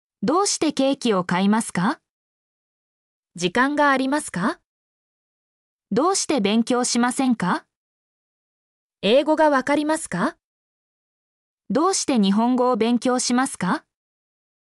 mp3-output-ttsfreedotcom-37_7TZV0XYX.mp3